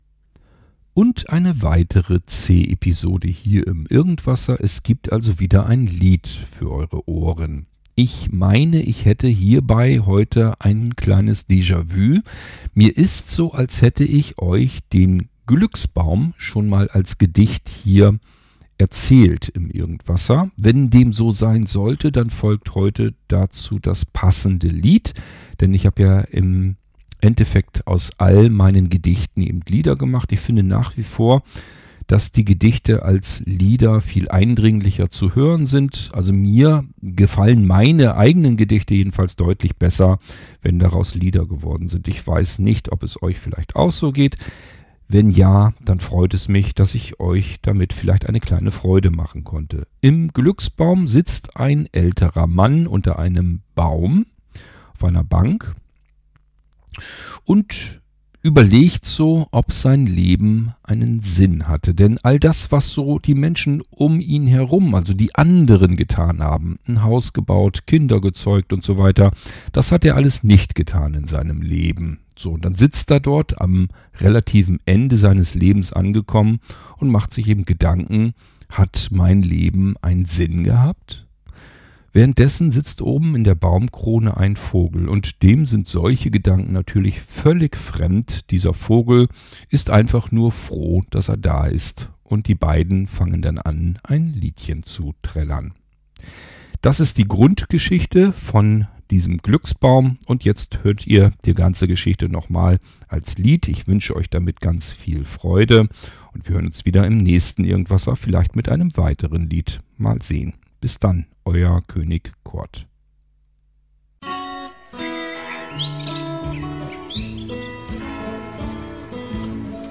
Ein Mann und ein Vogel in einem Baum mit unterschiedlichen Gedanken singen ein Lied.